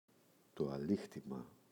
αλύχτημα, το [aꞋlixtima]